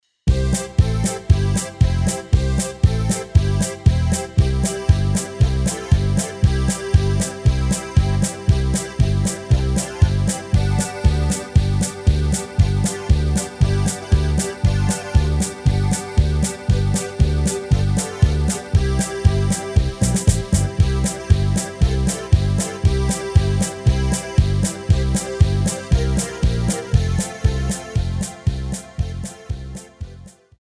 Rubrika: Národní, lidové, dechovka
- polka
2 - takty - Cdur